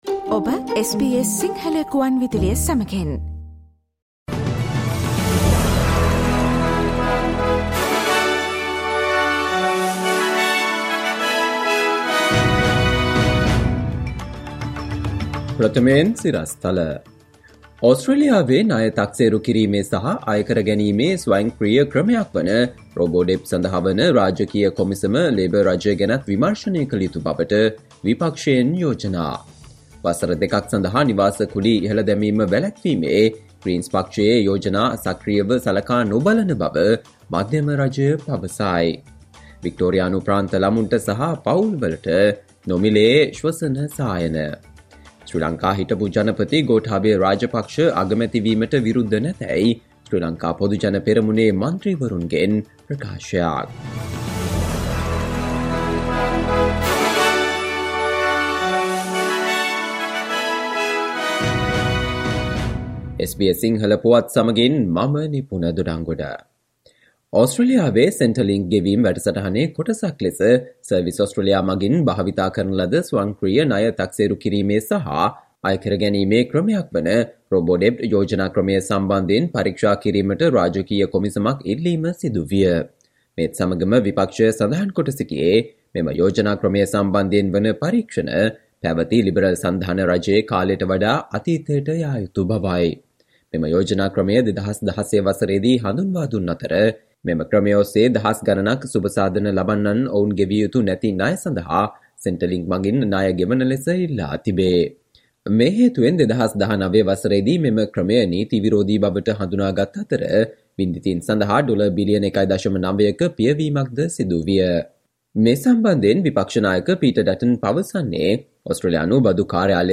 Listen to the SBS Sinhala Radio news bulletin on Friday 26 August 2022